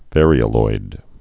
(vârē-ə-loid, văr-, və-rīə-loid)